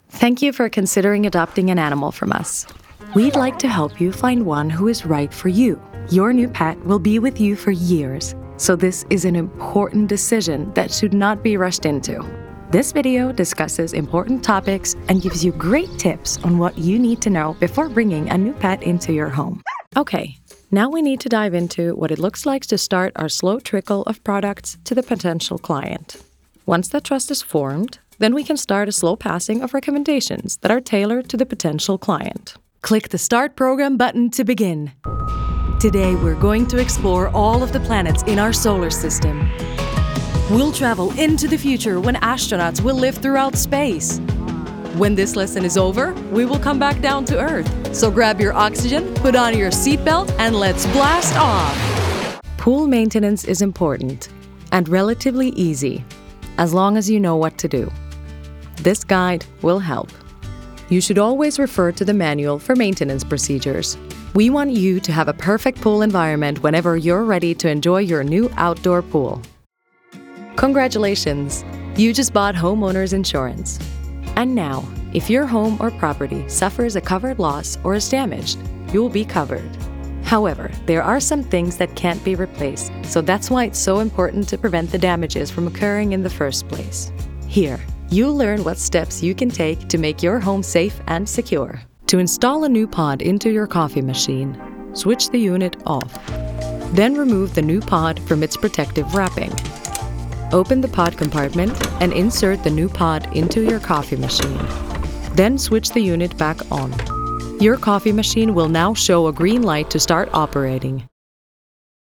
E-learning